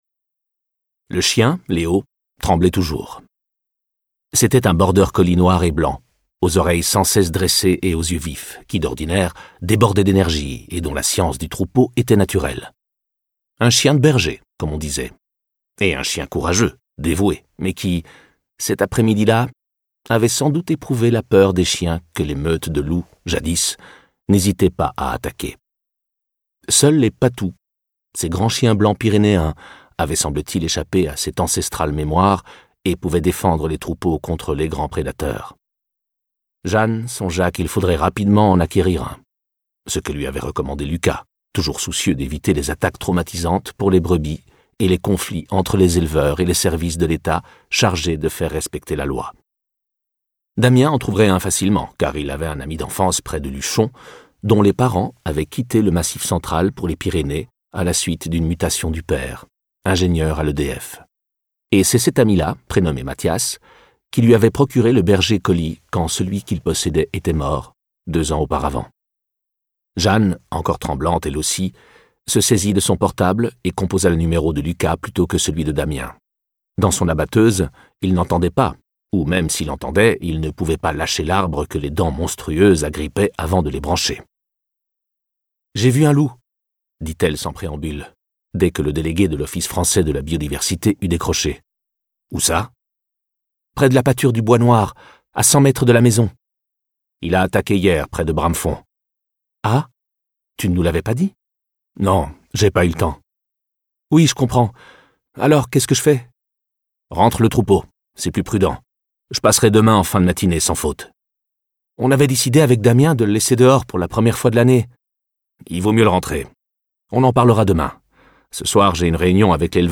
Diffusion distribution ebook et livre audio - Catalogue livres numériques
Interprétation humaine Durée : 04H57 20 , 95 € précommande Ce livre est accessible aux handicaps Voir les informations d'accessibilité